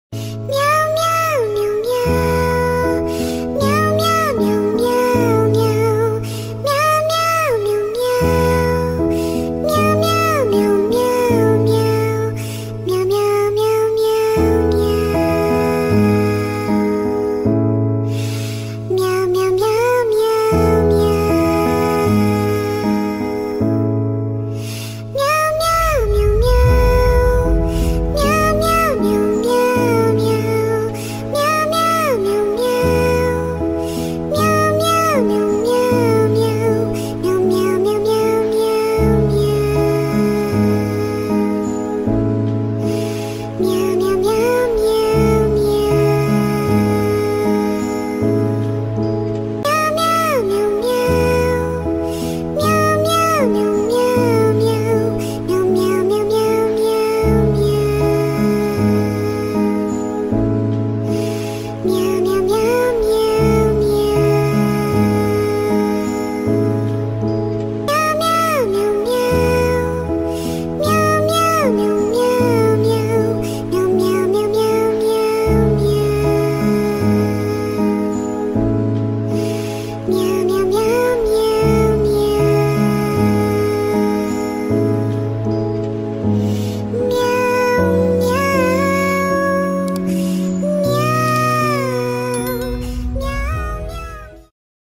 TikTok Remix version sped up